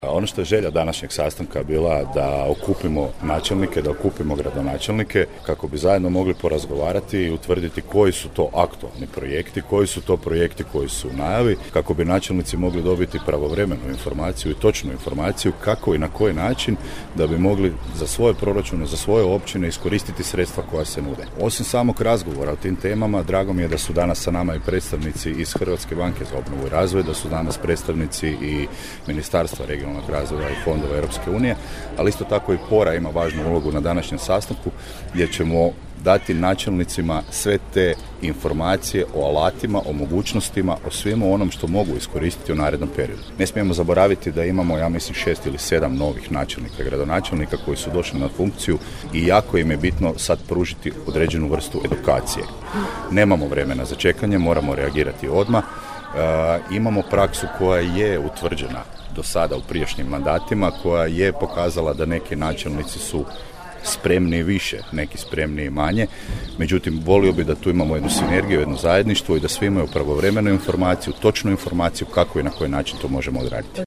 Koprivničko-križevački župan Tomislav Golubić je u Novigradu Podravskom okupio načelnike i gradonačelnike s područja Koprivničko-križevačke županije na prvom ovogodišnjem kolegiju.